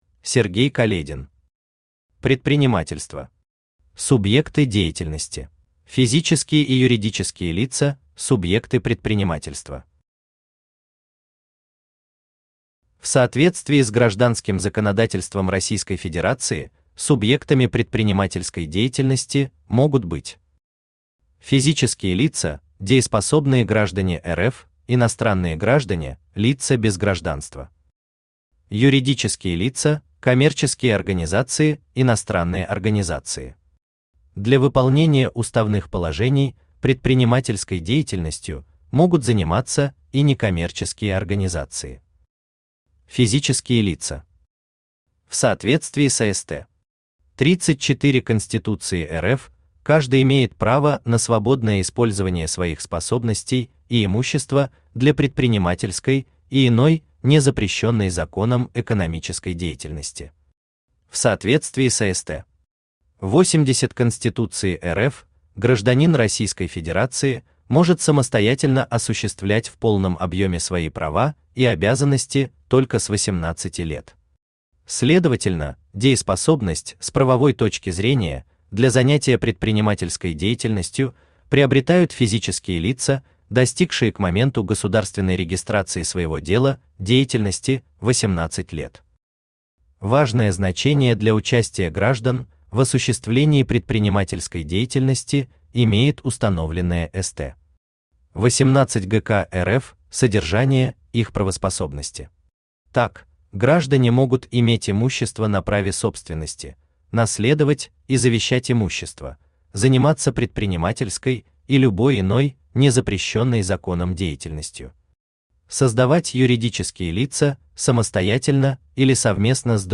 Аудиокнига Предпринимательство. Субъекты деятельности | Библиотека аудиокниг
Субъекты деятельности Автор Сергей Каледин Читает аудиокнигу Авточтец ЛитРес.